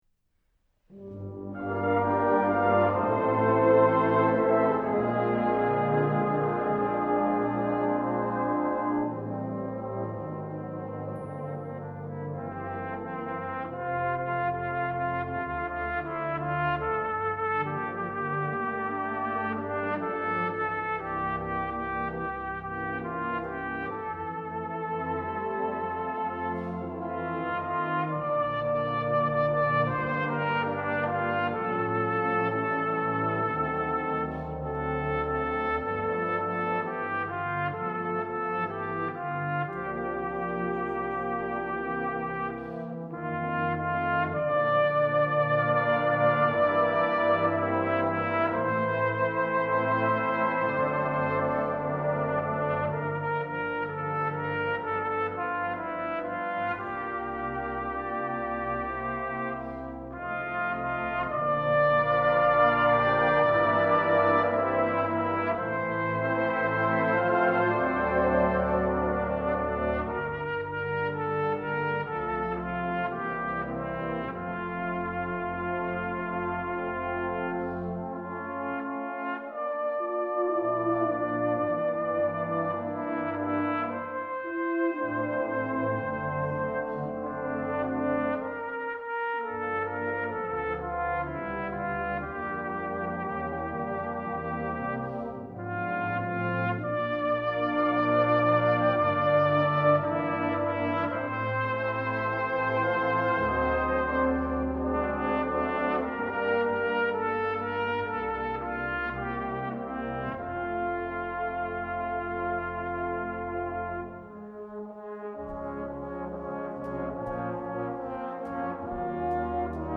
cornet solo